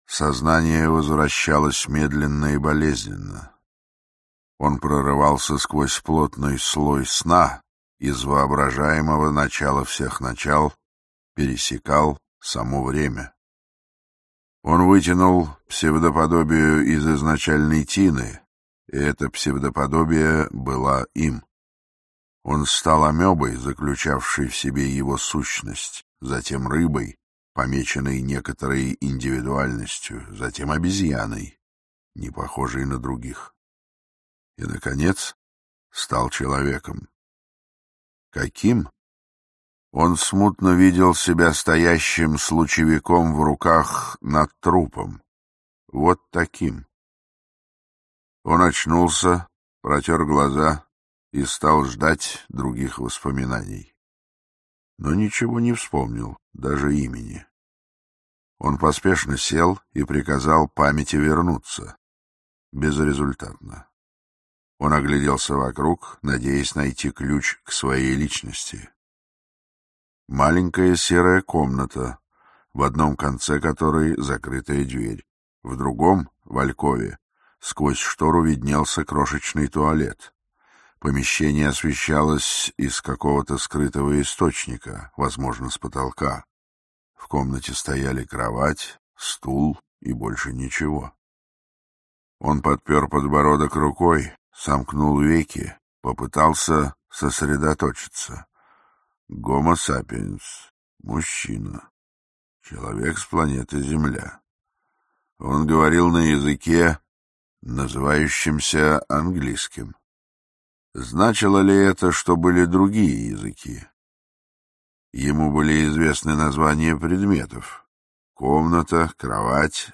Аудиокнига Цивилизация статуса | Библиотека аудиокниг
Aудиокнига Цивилизация статуса Автор Роберт Шекли Читает аудиокнигу Максим Суханов.